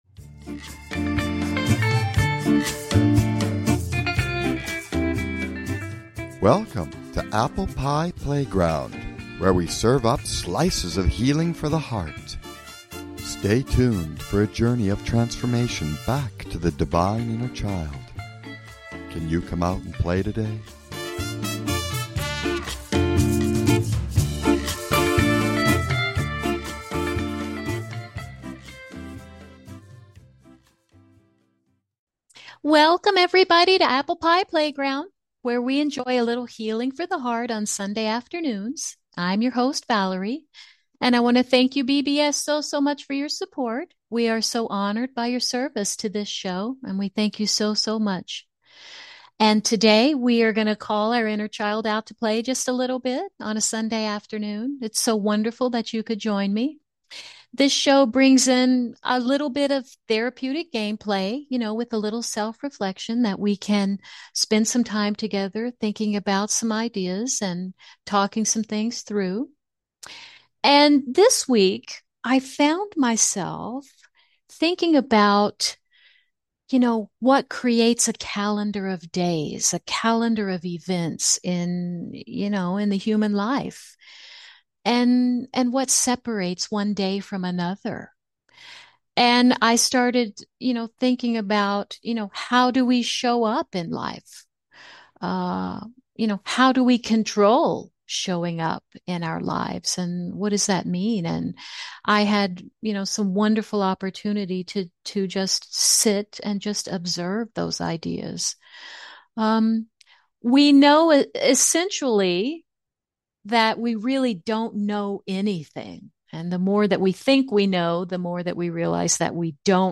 Talk Show Episode, Audio Podcast, Apple Pie Playground and Self-reflection, embracing our fuller energetic, spiritual selves and identifying source on , show guests , about self-reflection,embracing Source,spiritualistic Self,identifying source,Source, categorized as Education,Alternative Health,Energy Healing,Kids & Family,Philosophy,Emotional Health and Freedom,Personal Development,Self Help,Spiritual